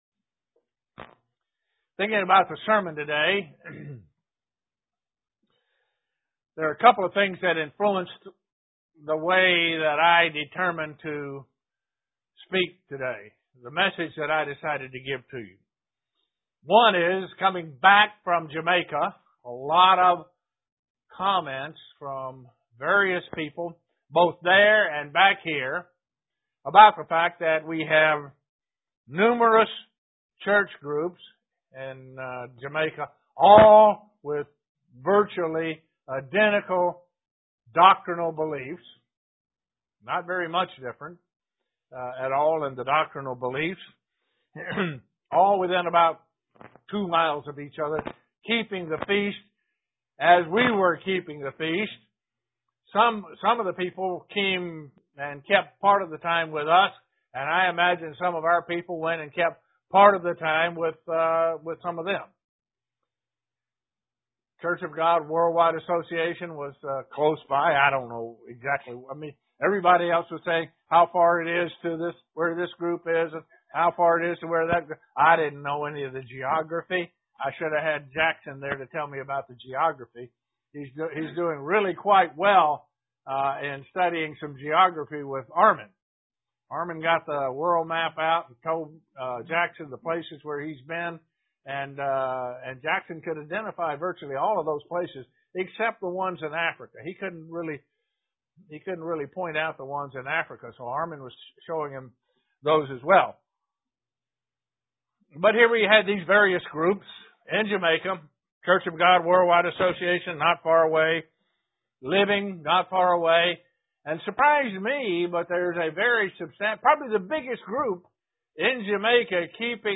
Given in Elmira, NY
Print Make sure that the spirit that is leading you is the Holy Spirit of God UCG Sermon Studying the bible?